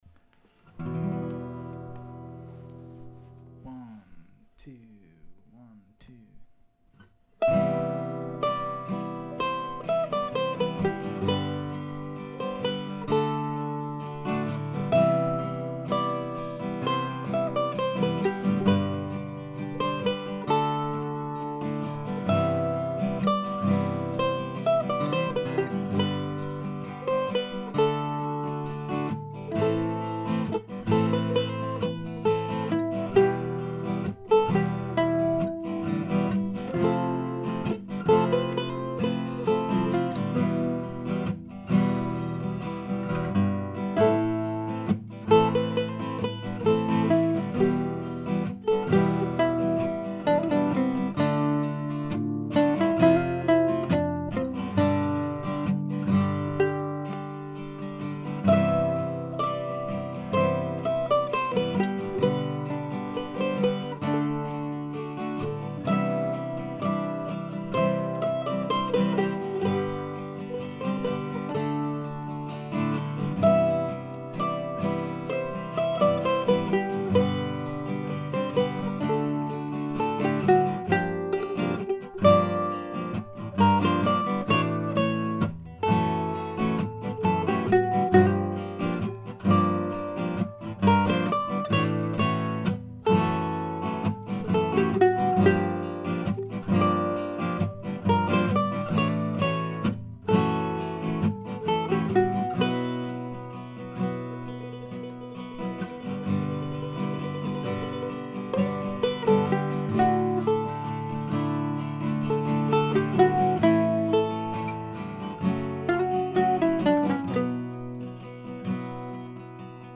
I considered making a big multi-track production of this piece but opted instead for a simpler take.